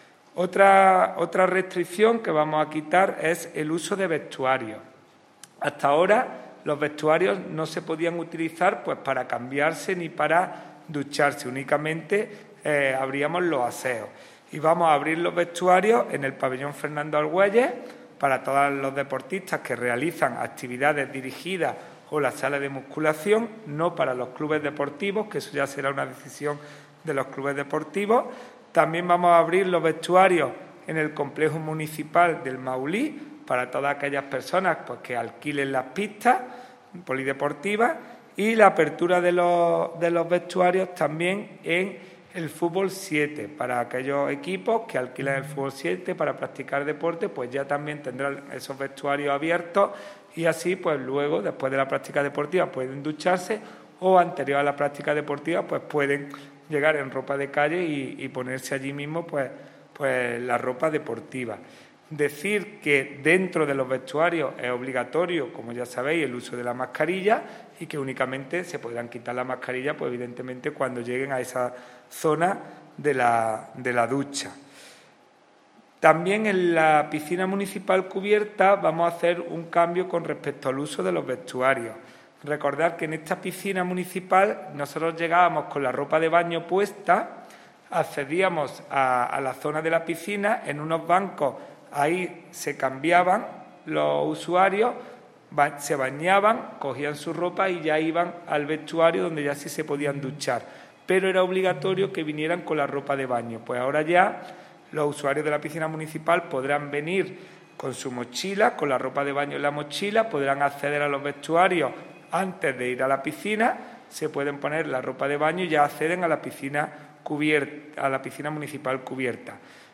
El teniente de alcalde delegado de Deportes del Ayuntamiento de Antequera, Juan Rosas, ha confirmado en rueda de prensa la entrada en vigor –a partir de este lunes 18 de octubre– de nuevas normas menos restrictivas en el uso y participación en actividades deportivas municipales que se celebran en recintos como el Pabellón Fernando Argüelles o la Piscina Cubierta Municipal, así como la puesta en disposición para su reserva y alquiler de varias pistas deportivas destinadas a la práctica de deportes en equipo.
Cortes de voz